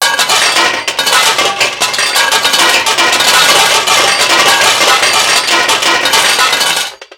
fix-machine.wav